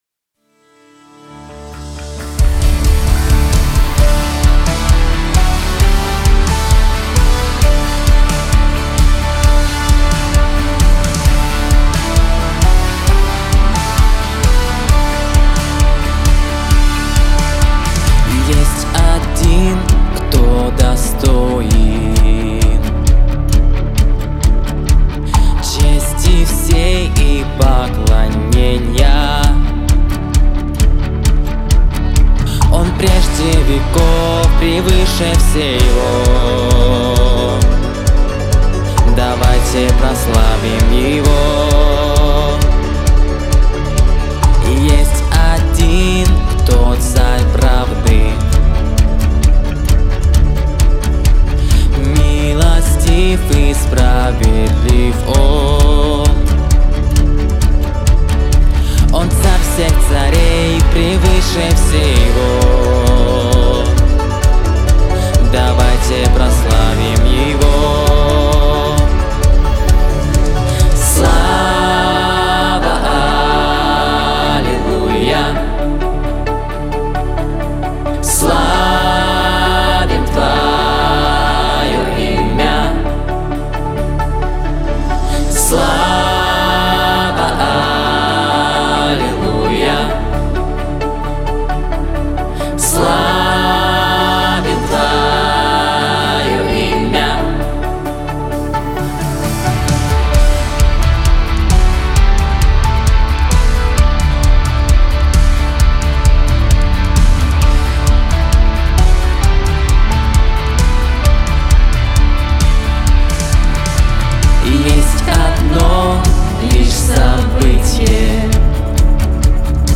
101 просмотр 94 прослушивания 0 скачиваний BPM: 132
(Live)